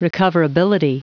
Prononciation du mot recoverability en anglais (fichier audio)
Prononciation du mot : recoverability